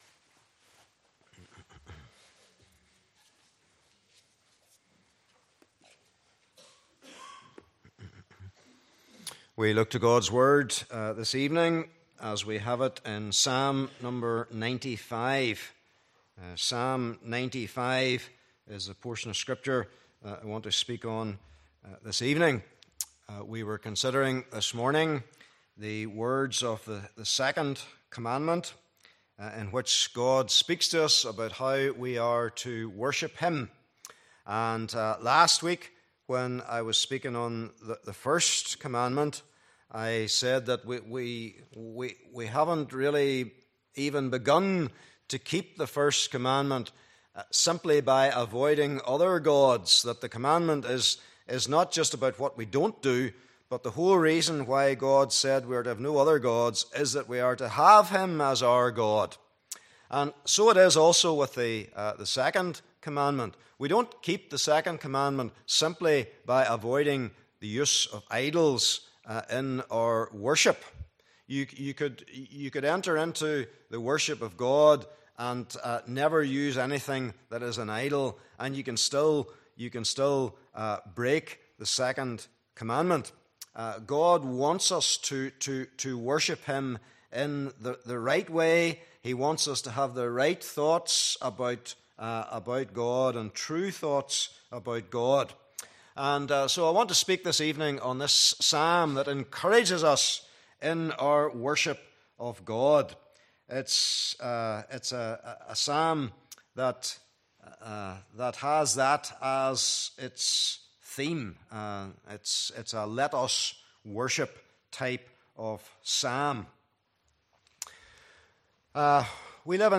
Passage: Psalm 95:1-11 Service Type: Evening Service Bible Text